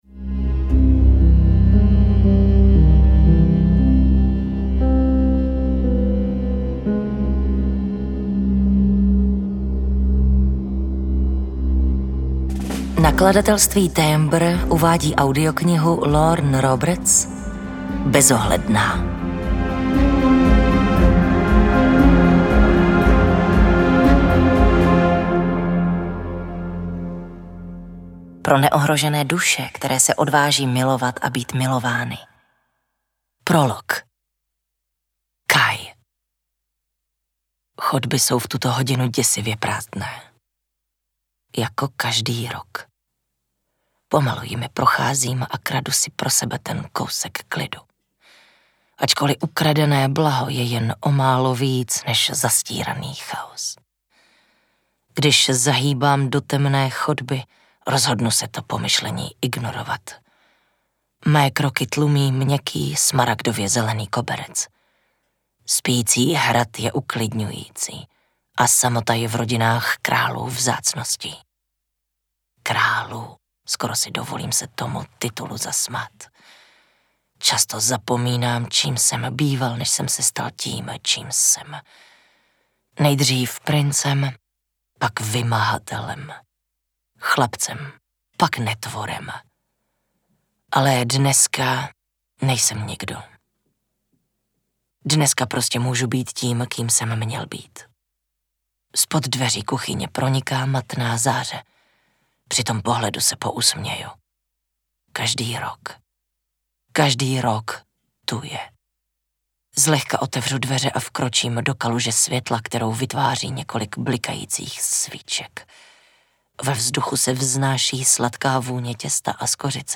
Bezohledná audiokniha
Ukázka z knihy